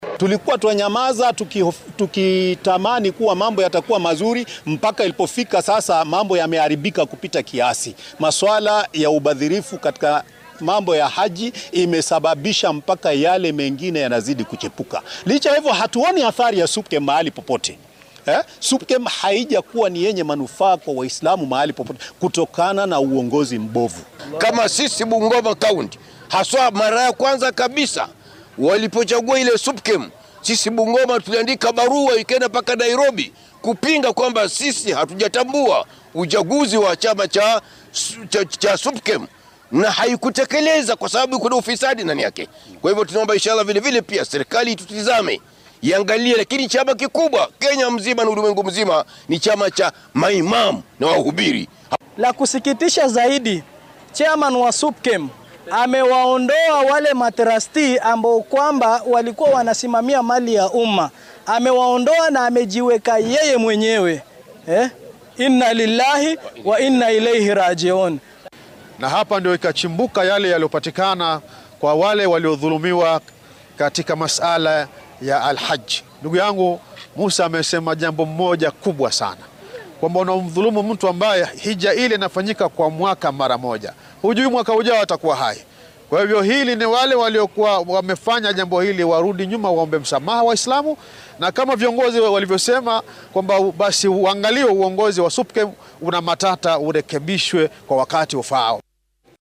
Imaamyada muslimiinta ah ee gobolka Galbeedka ayaa doonaya in isbedel lagu sameeyo golaha sare ee muslimiinta Kenya (SUPKEM), iyagoo ka cabanaya wax ay ku tilmaameen hufnaan la’aan iyo kala qeybsanaan joogto ah. Isagoo ka hadlayay shir ay isugu yimaadeen imaamyada iyo daaciyiinta galbeedka Kenya oo lagu qabtay masjidka Jamia ee magaalada Malaba ee ismaamulka Busia ayaa madaxda ay ku baaqeen in xilalka laga qaado qaar ka mid ah maamulayaasha SUPKEM isla markaana xilalka loo dhiibo xubno cusub.